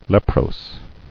[lep·rose]